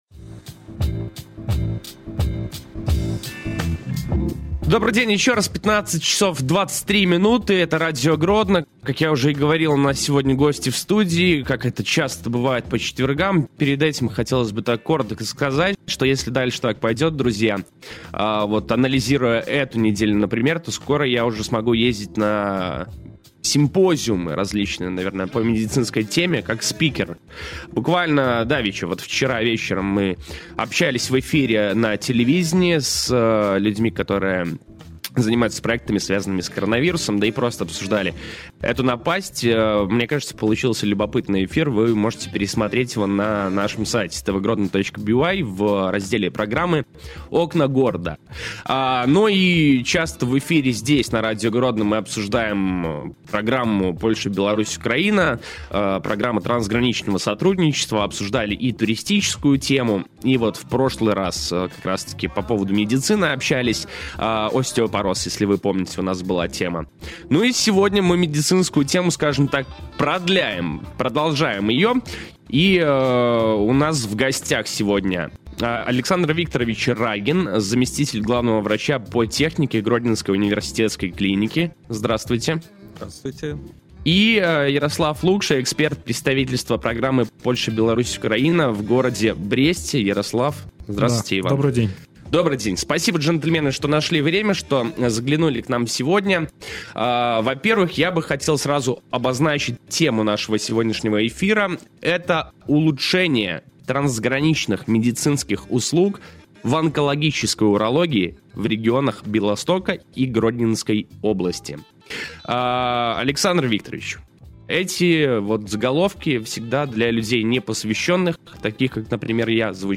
Kontynuujemy cykl wywiadów radiowych z przedstawicielami projektów realizowanych w ramach naszego Programu na Białorusi.